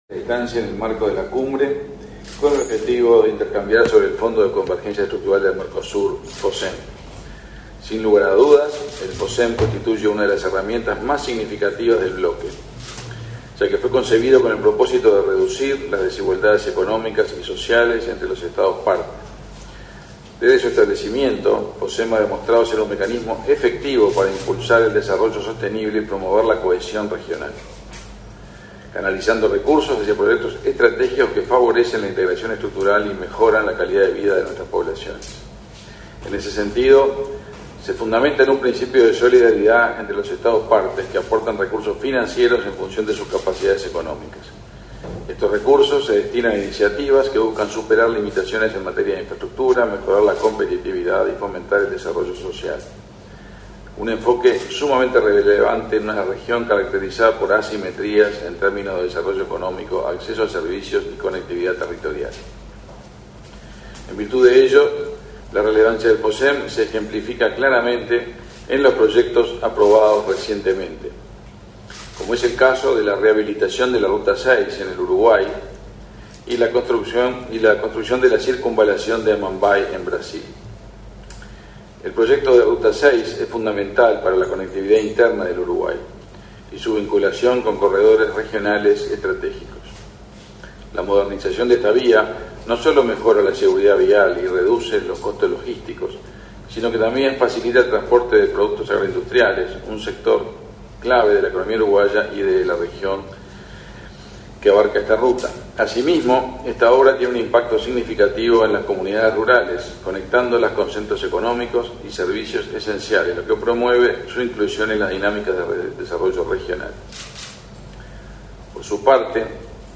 Palabras del canciller de la República, Omar Paganini
Palabras del canciller de la República, Omar Paganini 05/12/2024 Compartir Facebook X Copiar enlace WhatsApp LinkedIn Durante la primera jornada de la LXV Cumbre del Mercosur, este 5 de diciembre, Uruguay firmó un acuerdo para financiar obras de infraestructura a través del Fondo para la Convergencia Estructural del Mercosur (Focem). El canciller, Omar Paganini, realizó declaraciones.